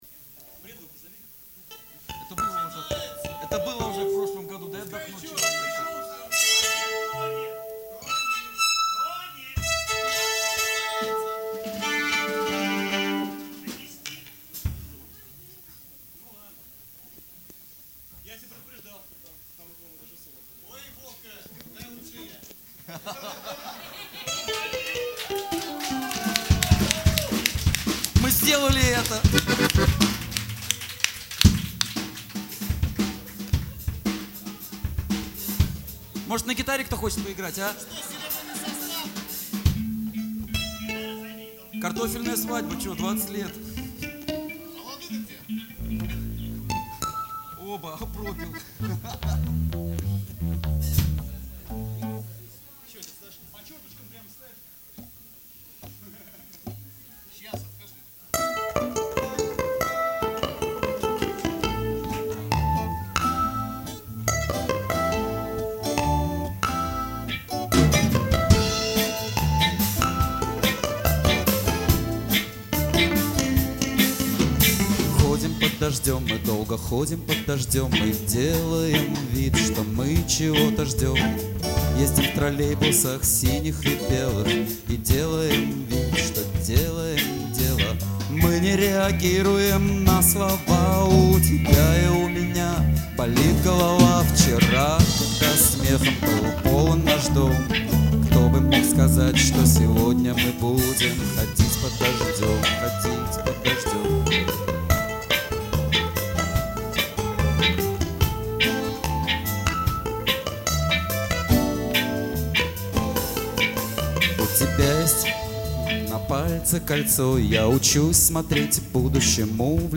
Live в клубе "Манхэттен", 11.07.2007